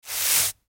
Звуки метлы
Один раз прошлись метёлкой